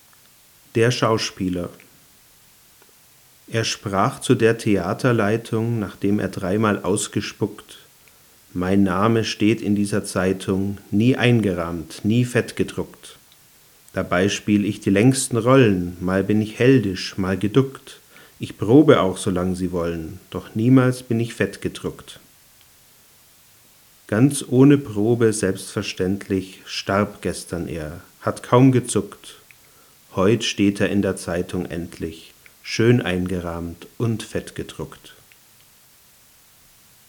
Um das gefürchtete Rauschen zu vergleichen, habe ich ein dynamisches Mikrofon benutzt und aus 60 cm Abstand besprochen.
Anschließend wurden alle Aufnahmen noch normalisiert, damit ein echter Vergleich des Rauschens möglich ist.
Mikrofon direkt am Mic-Eingang des Camcorders Canon XA10
canon_xa10.mp3